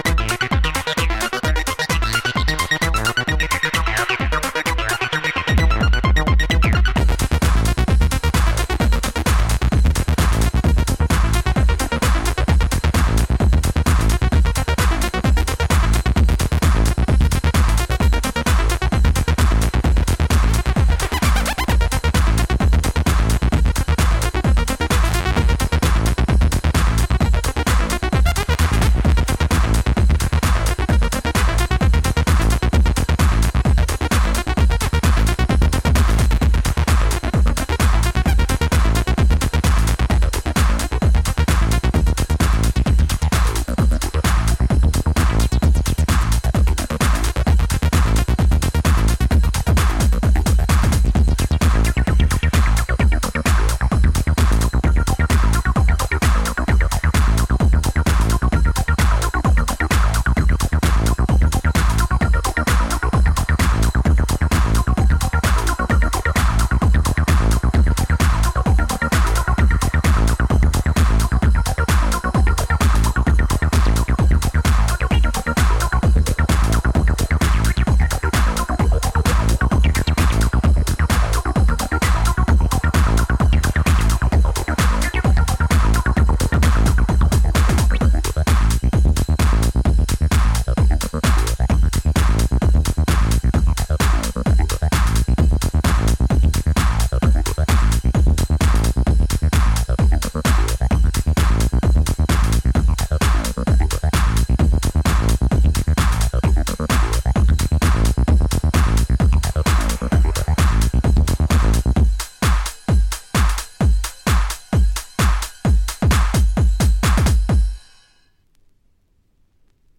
ハードストンピンなミニマルアシッド